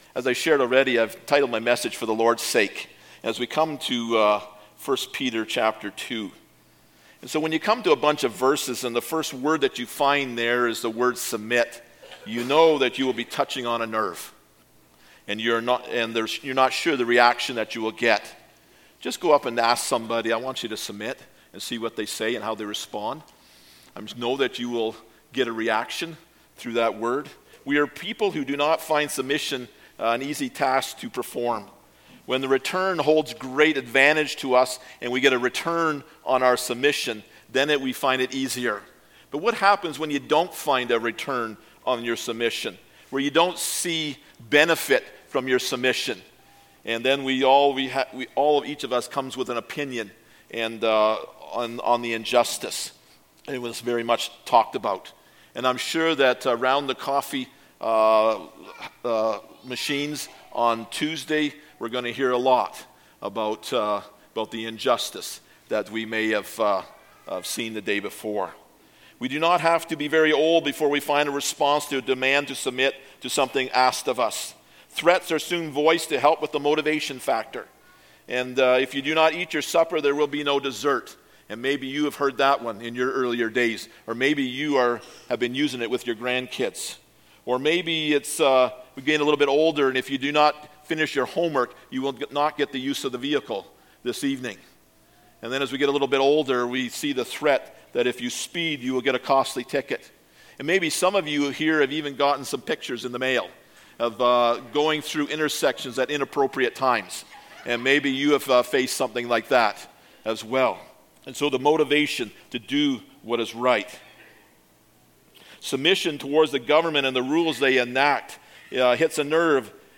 Passage: 1 Peter 2:13-25 Service Type: Sunday Morning